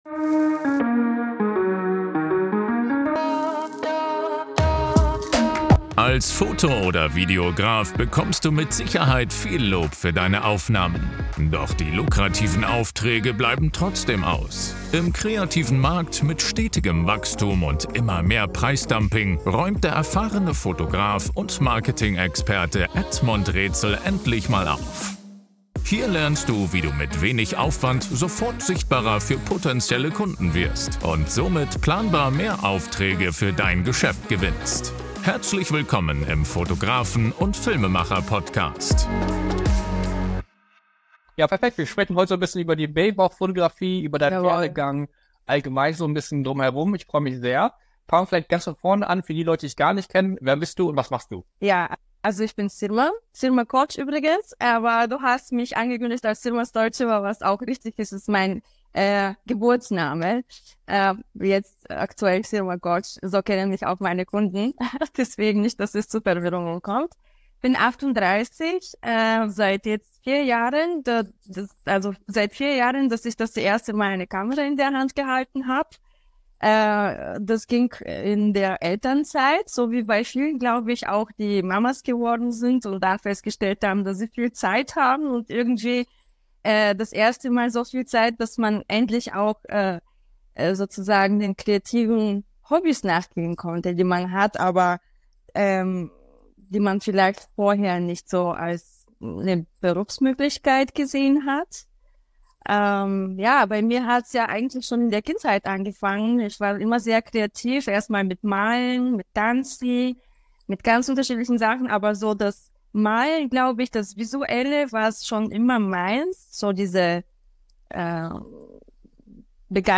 Interview ~ Fotografen und Filmemacher Podcast - Fotografie und Film Podcast